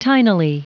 Prononciation du mot tinily en anglais (fichier audio)
Prononciation du mot : tinily